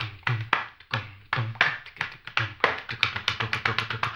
HAMBONE 17-L.wav